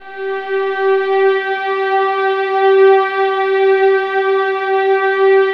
VIOLINS .1-R.wav